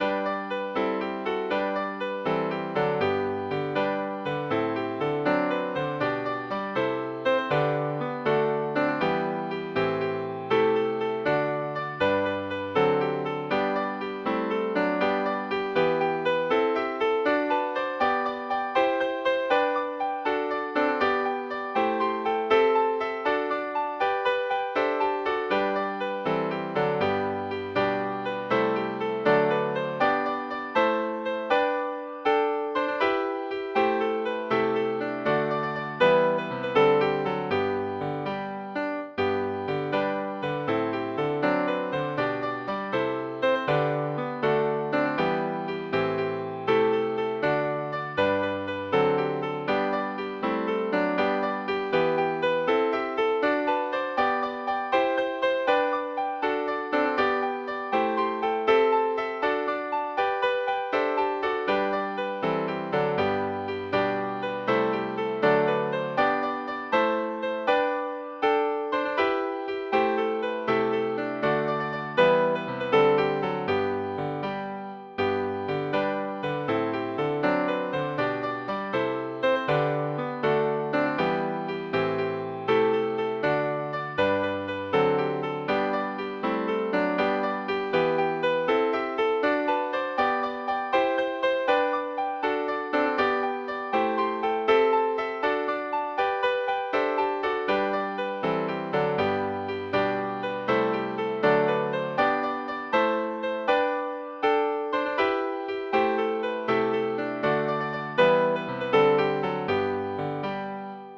Midi File, Lyrics and Information to Lord Thomas and Fair Ellinor